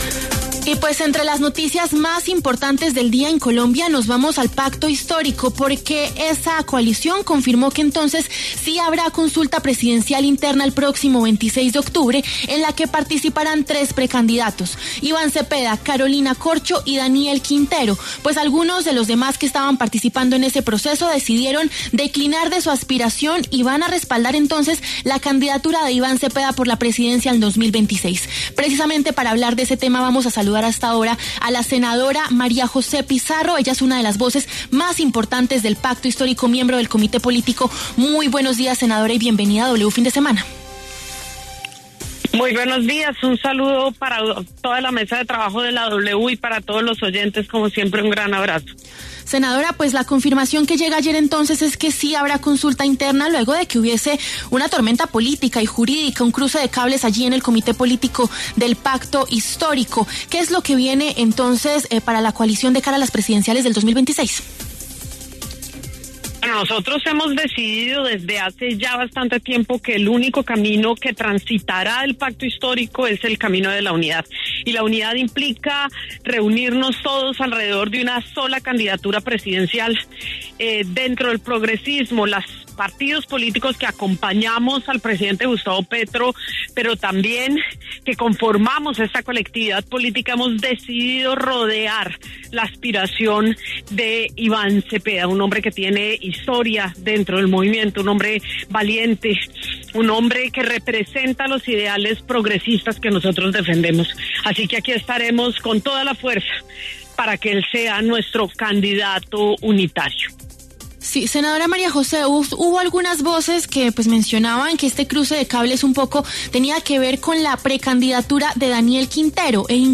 La senadora María José Pizarro se refirió en W Fin de Semana a la decisión de hacer a un lado su aspiración a la Presidencia para respaldar a Iván Cepeda.